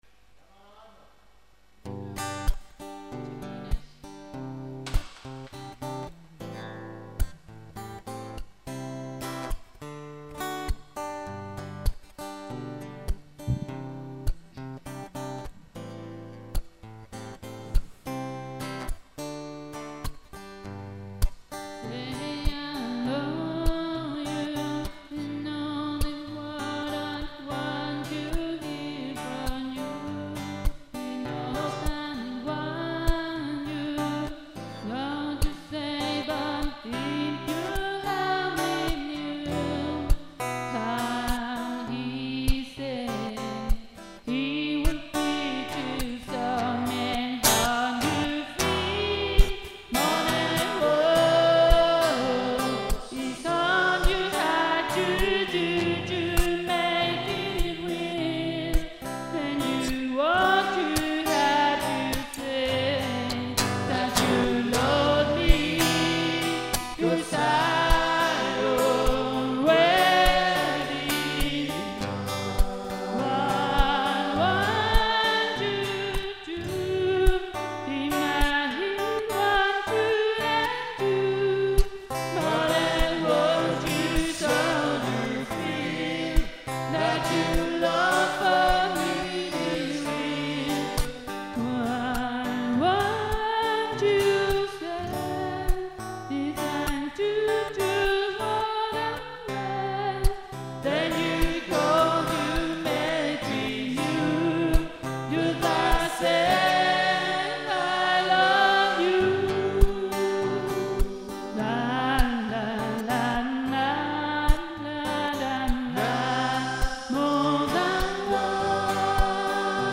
Voz solista
Segunda voz
Guitarra acústica